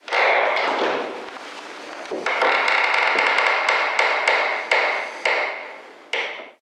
Muelles de una cama
cama muelle ruido somier
Sonidos: Hogar